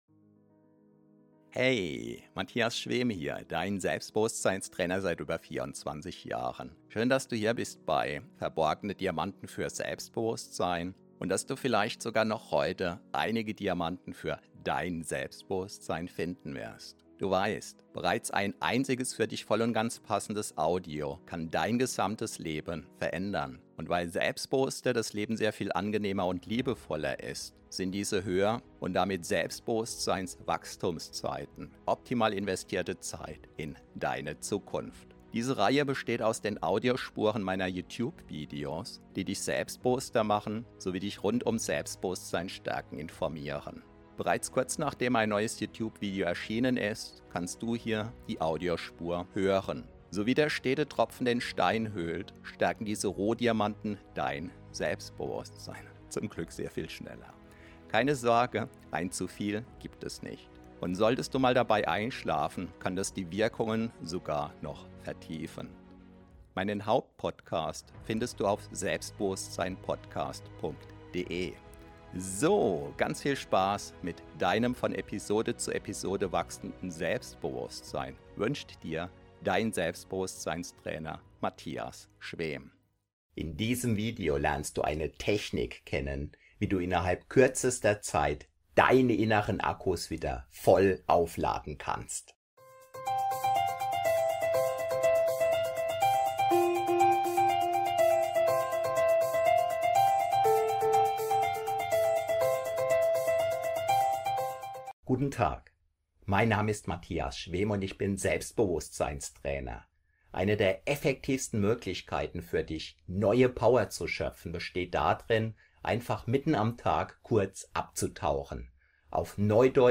Powernap 10 min Power Nap deutsch Powerschlaf Tiefenentspannung Stressabbau Selbsthypnose Meditation ~ Verborgene Diamanten Podcast [Alles mit Selbstbewusstsein] Podcast